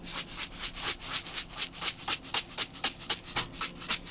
R - Foley 46.wav